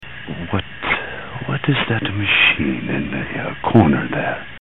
Machine